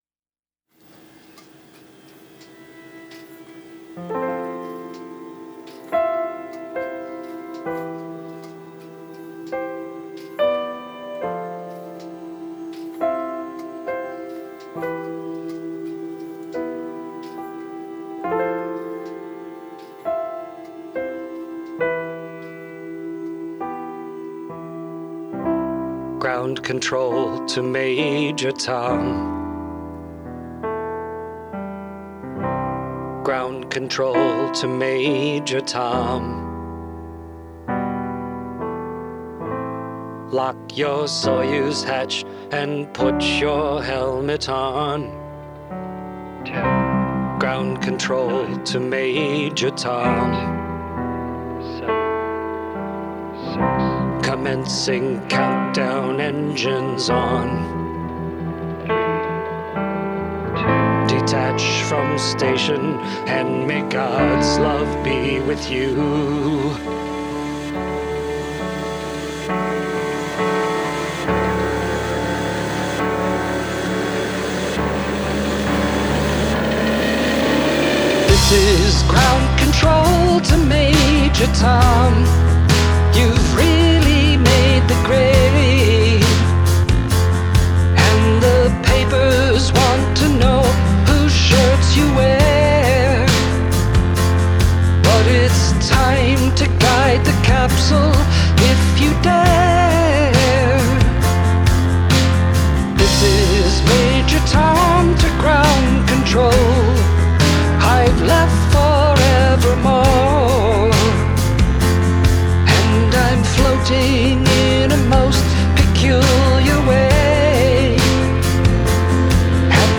Guitars, Bass, Programming, Keyboards